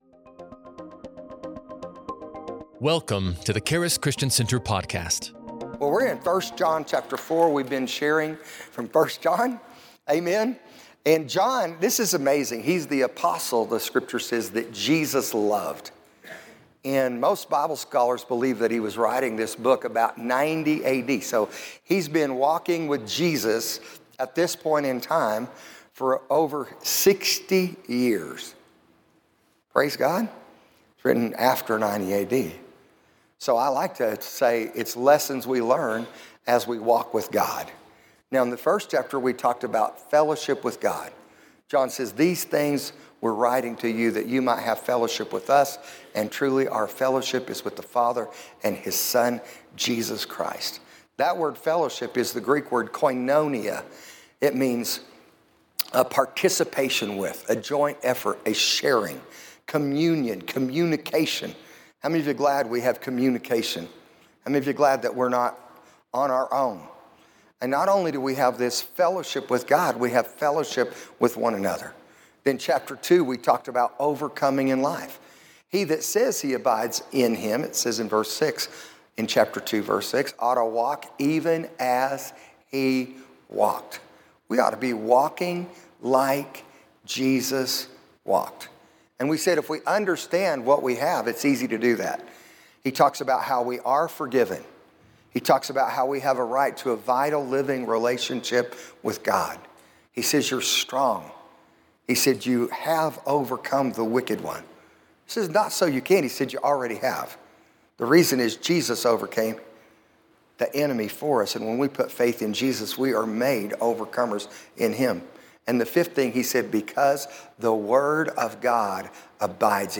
Love casts out fear, so we’re bold, not scared of judgment. Pastor shares stories of standing for Jesus, like billboard battles, showing faith wins.